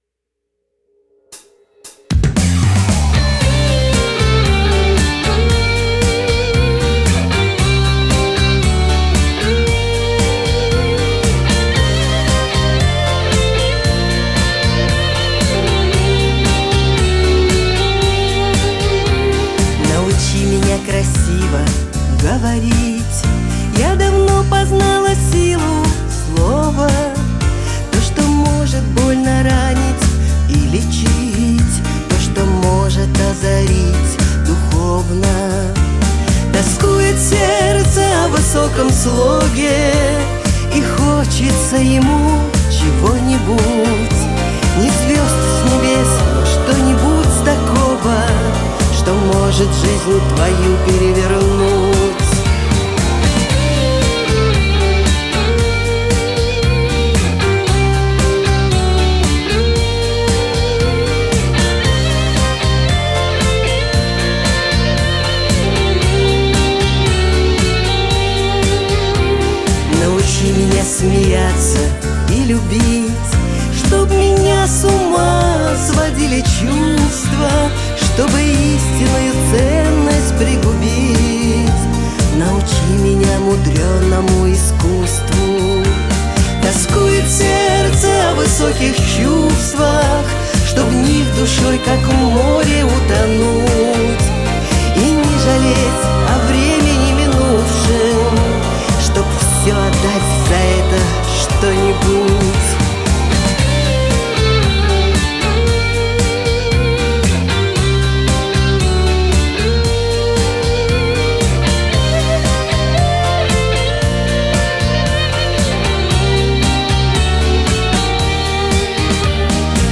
вокал
гитары, бэк-вокал